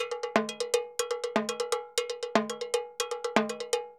Timbaleta_Baion 120_2.wav